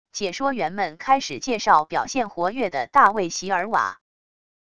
解说员们开始介绍表现活跃的大卫・席尔瓦wav音频